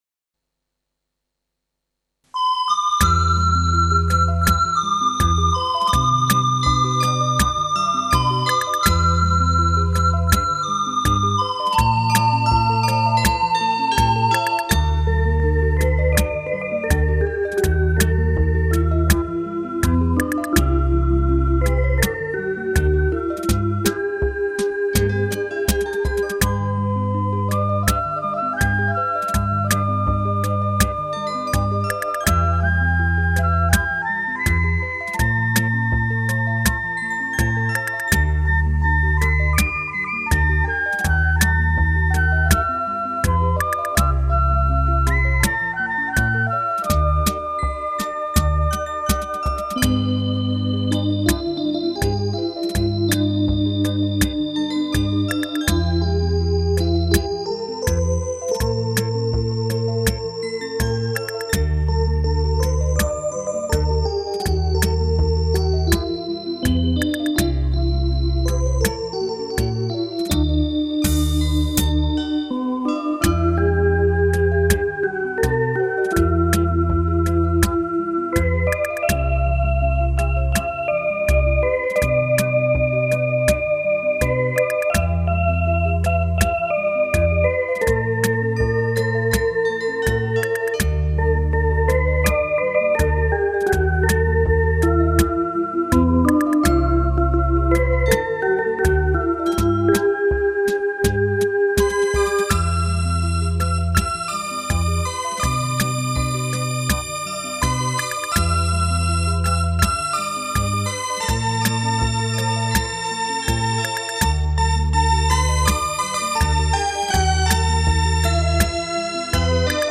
0074-电子琴名曲二泉吟.mp3